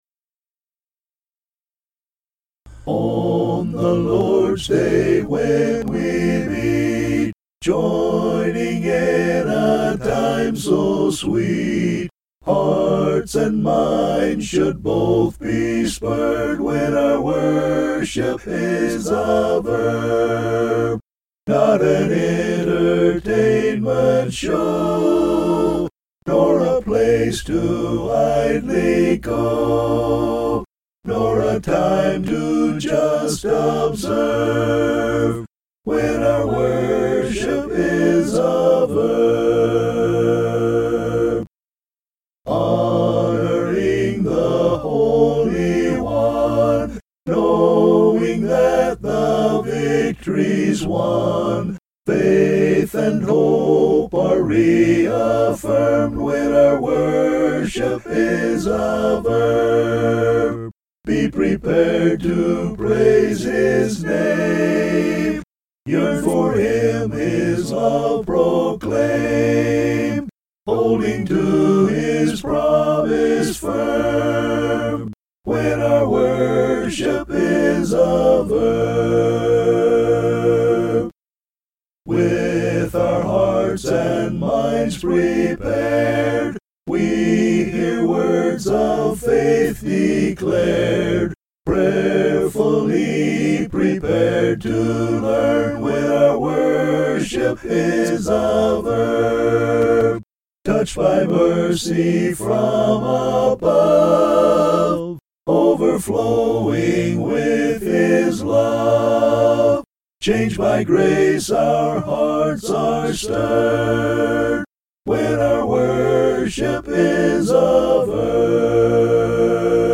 (An original hymn)
vocals